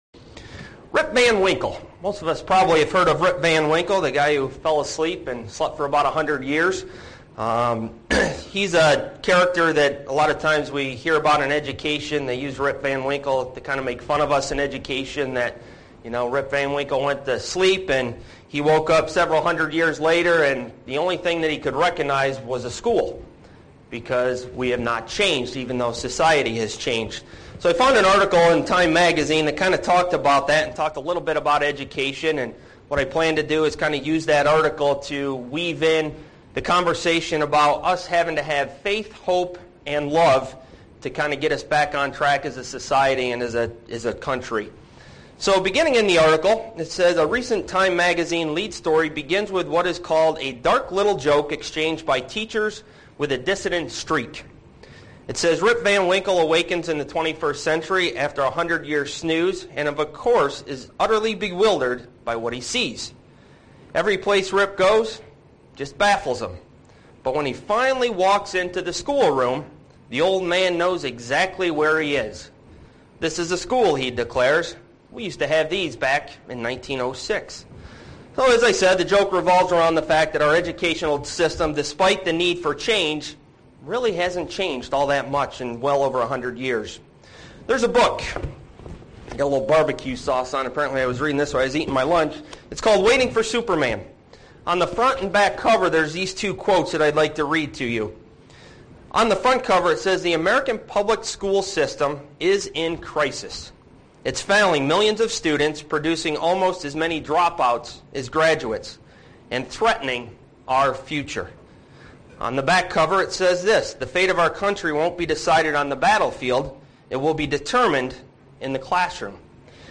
This sermon goes through the the basic teaching of God. There is always more to learn from studying deeply into the basics.
Given in Buffalo, NY